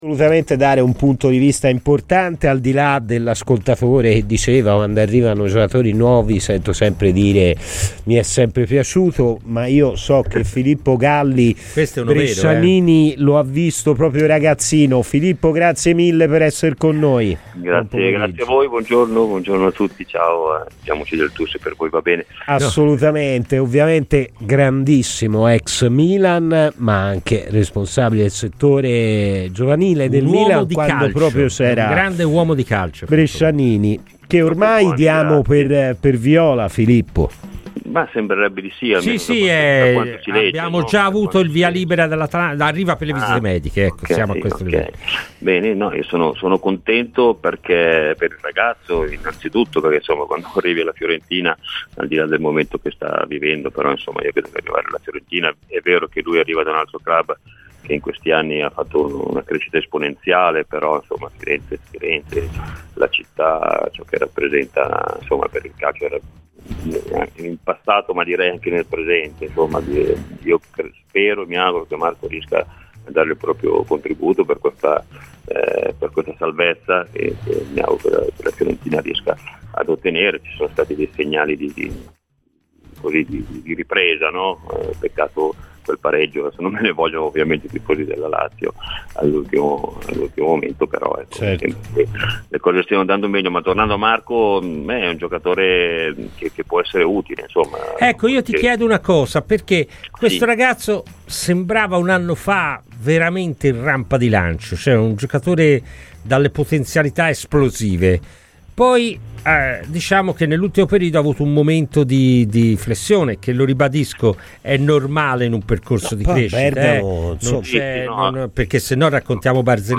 Filippo Galli, ex calciatore del Milan, è intervenuto a Radio Firenze Viola durante "Palla al Centro" per parlare di Marco Brescianini, giocatore che ha conosciuto quando era direttore responsabile del Milan.